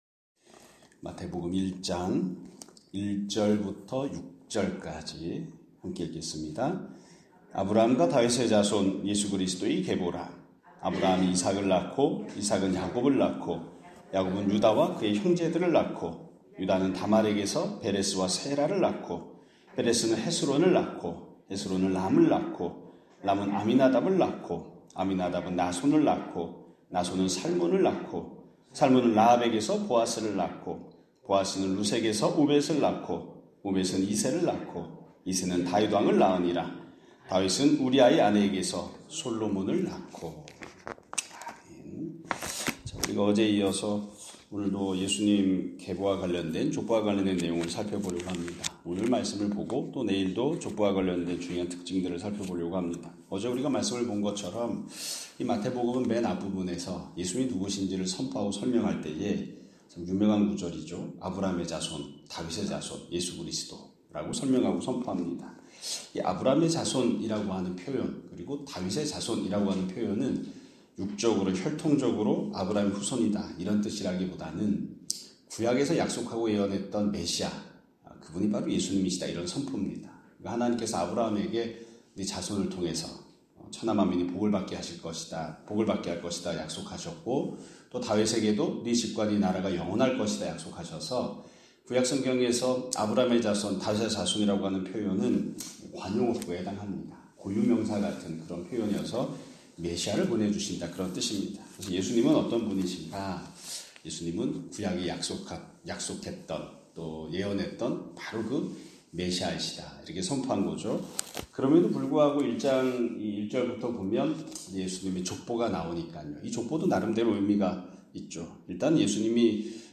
2025년 3월 20일(목요일) <아침예배> 설교입니다.